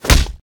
flesh3.ogg